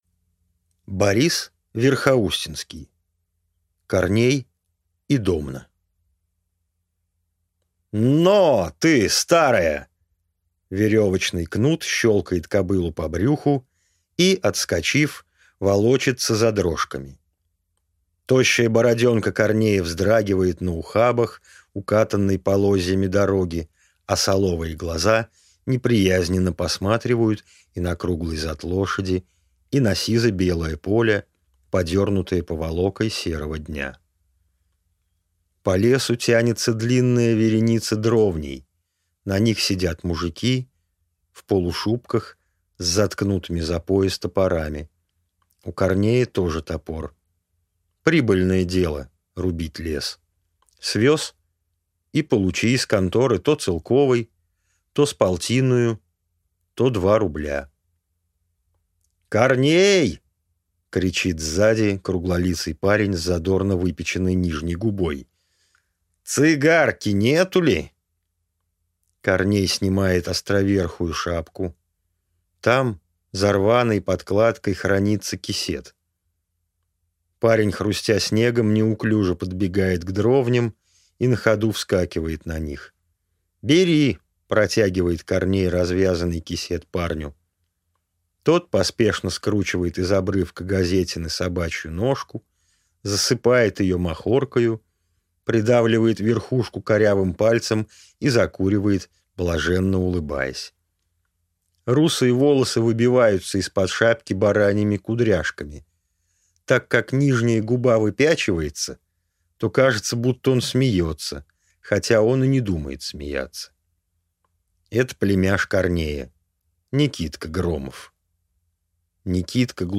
Аудиокнига Корней и Домна | Библиотека аудиокниг